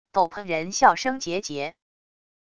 斗篷人笑声桀桀wav音频